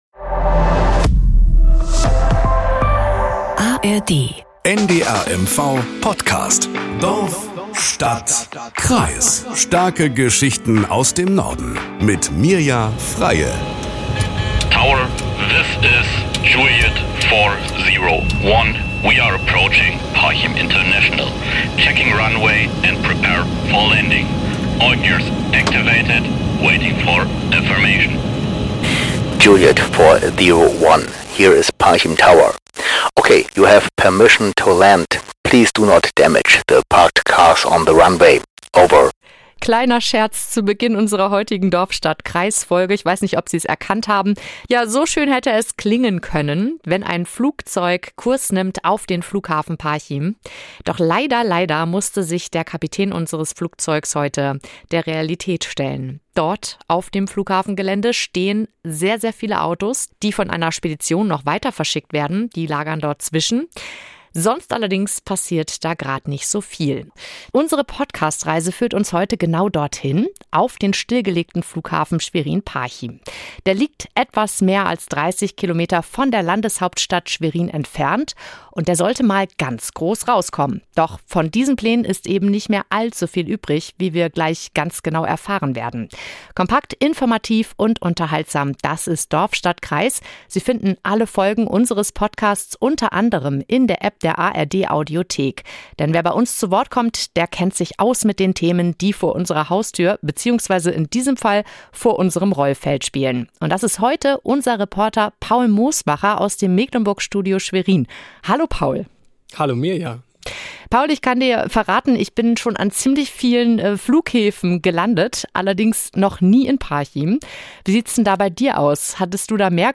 Nachrichten aus Mecklenburg-Vorpommern - 22.04.2024